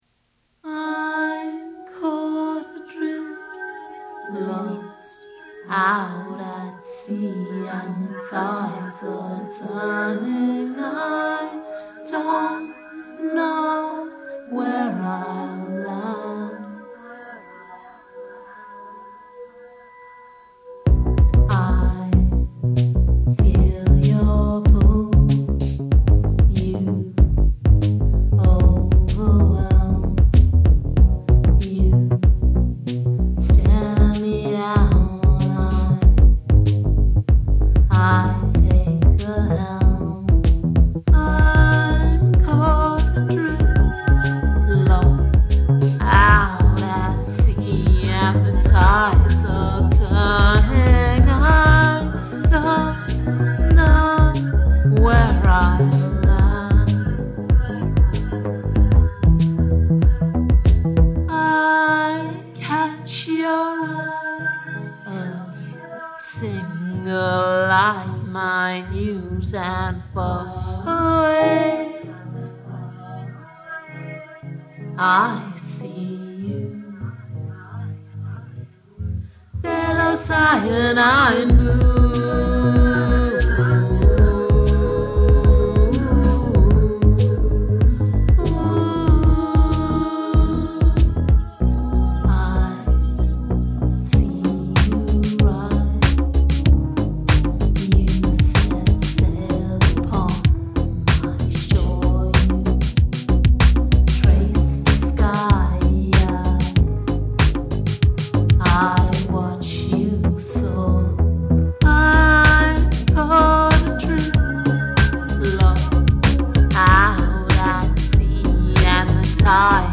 Love the beat, that synth bass grooves.
This chorus is really great.